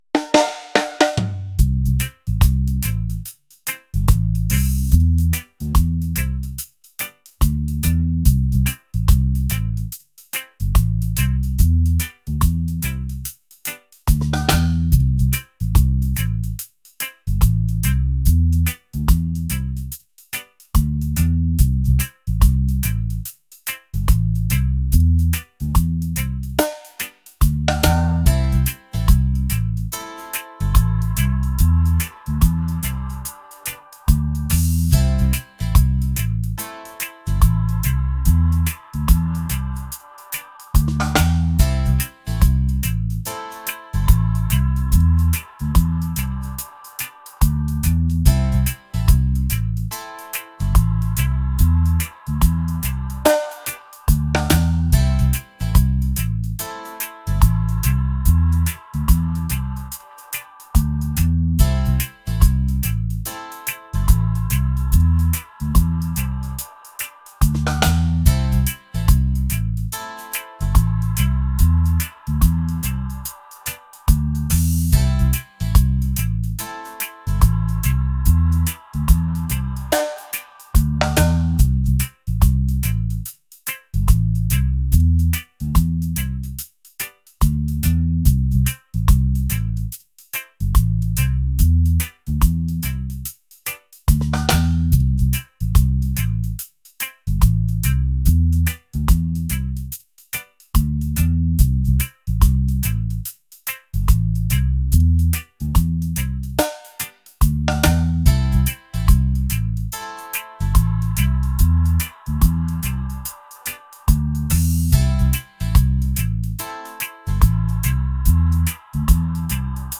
laid-back | reggae | smooth